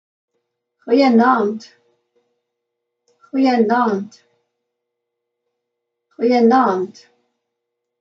Goo-uh-naand
8-Afrikaans-Good-evening.mp3